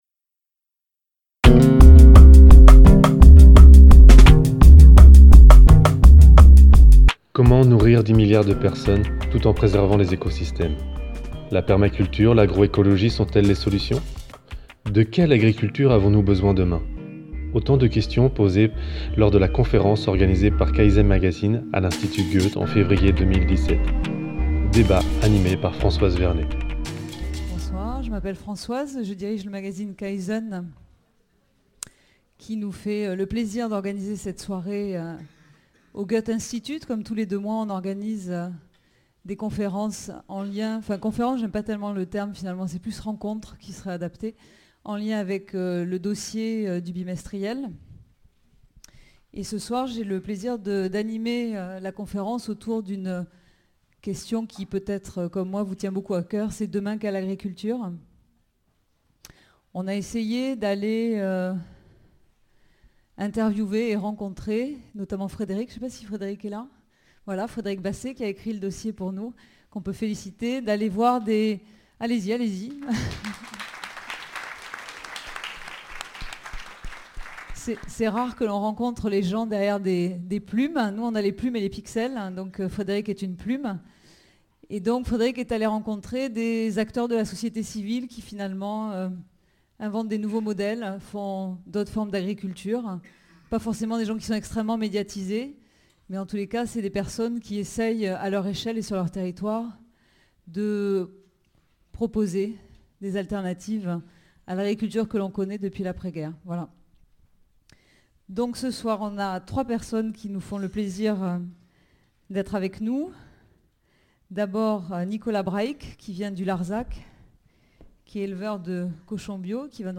Conférence Kaizen, demain quelle agriculture, 14 février 2016.